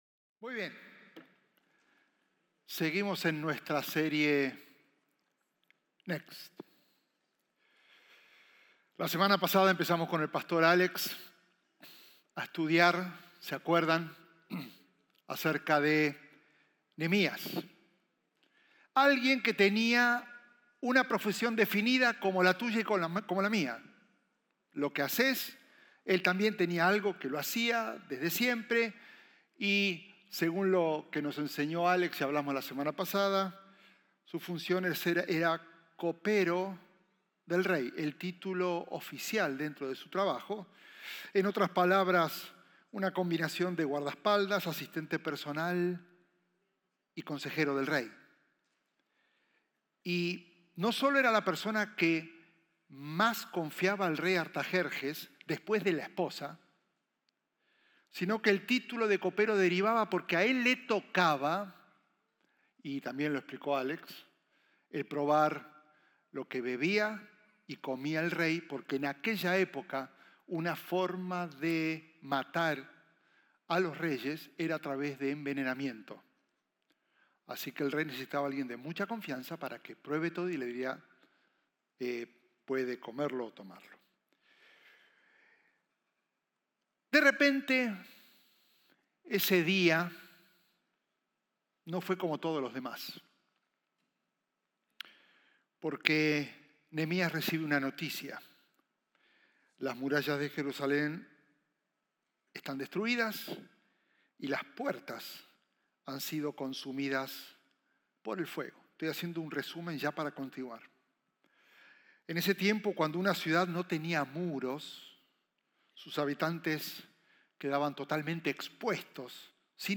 Sermones Conroe – Media Player